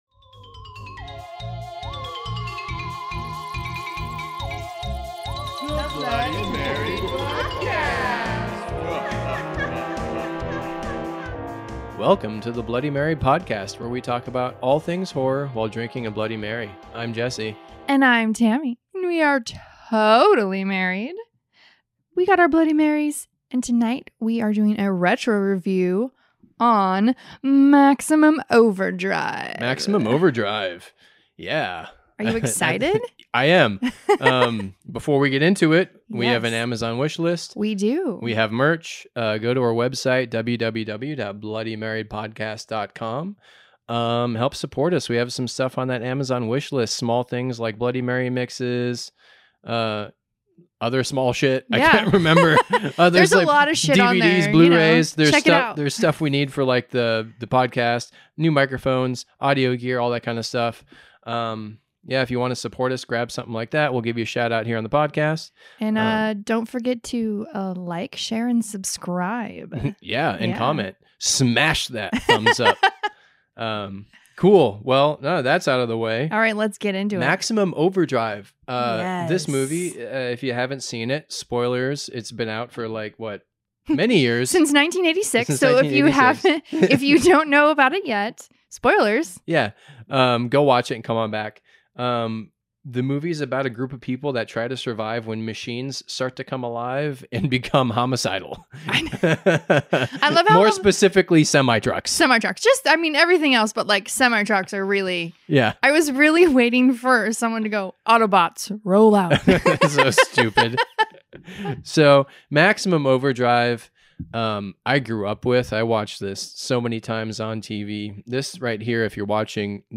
a totally rad couple, as they have a heart-to-heart about all things horror while drinking Bloody Marys.